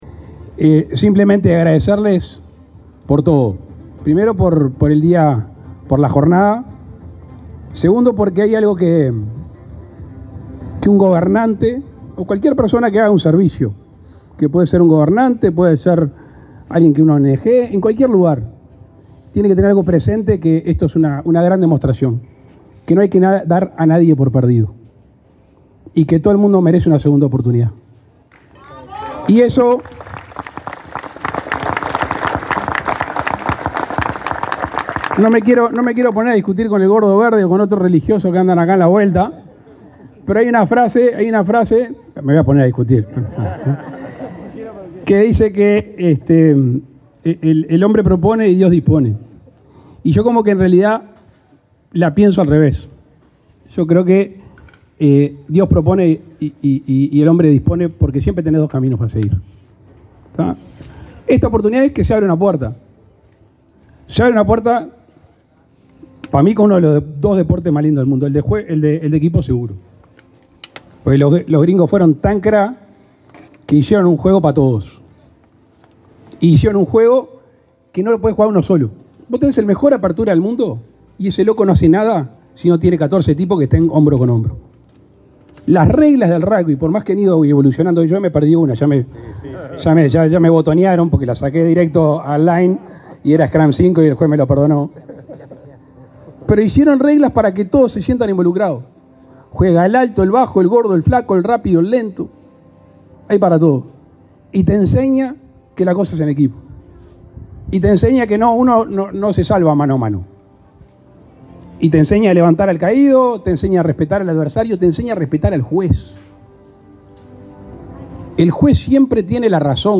Palabras del presidente Luis Lacalle Pou
El presidente de la República, Luis Lacalle Pou, presenció este martes 26 en Maldonado un encuentro internacional de rugby inclusivo entre dos equipos